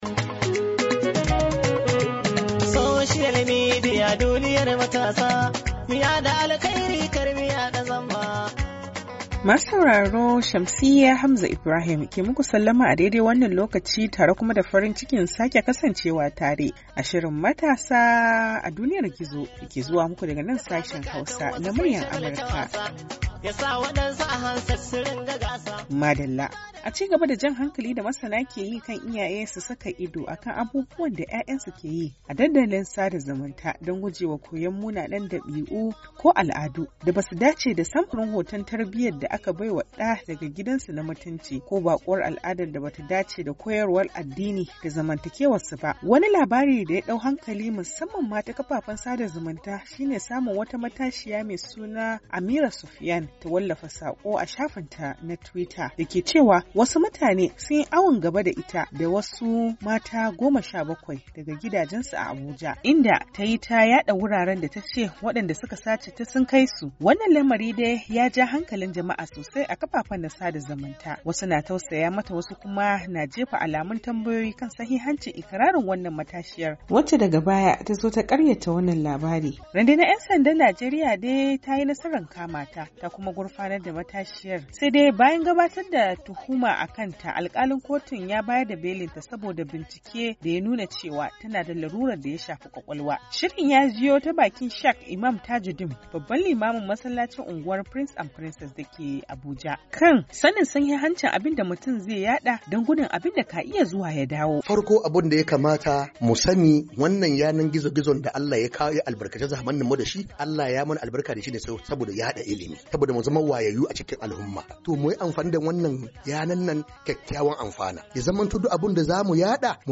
MATASA A DUNIYAR GIZO: Tattaunawa Kan Yadda Iyaye Za Su Rika Sa Ido Akan Abubuwan Da 'Ya'yansu Ke Yi A Kafafen Sada Zumunta - 7'07"